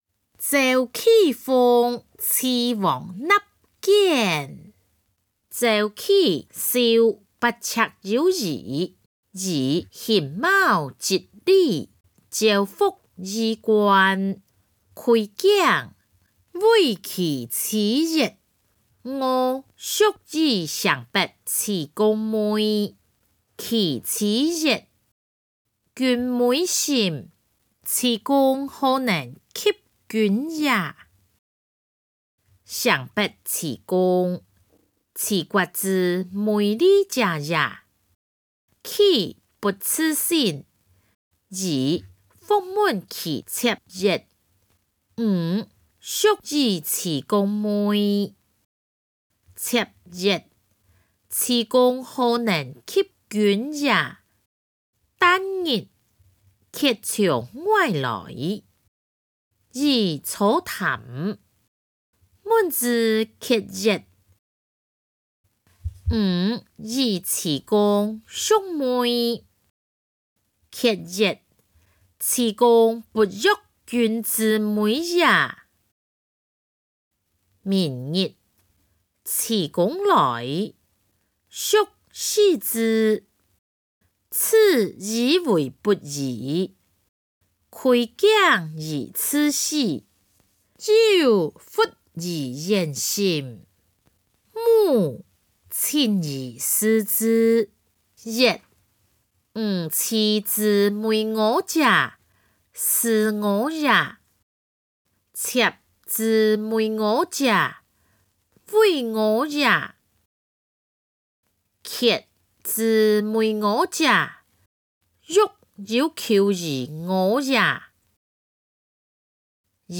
歷代散文-鄒忌諷齊王納諫音檔(大埔腔)